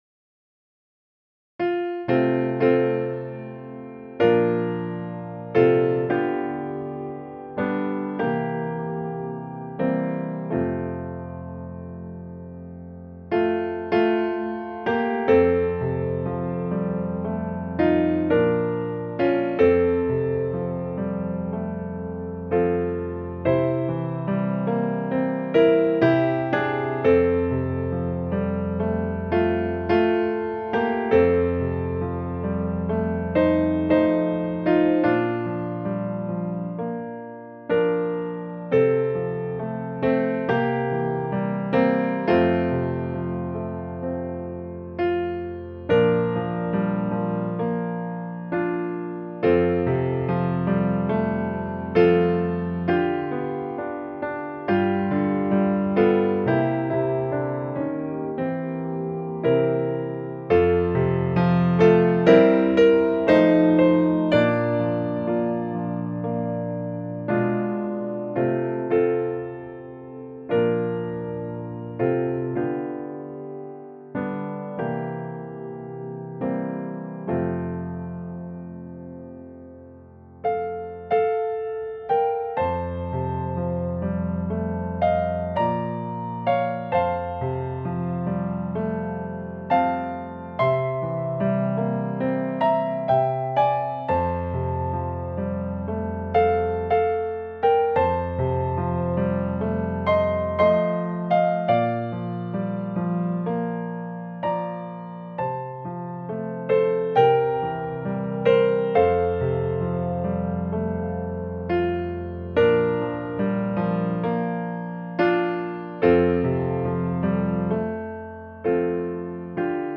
This hymn was written in 1914 by Ben H. Price.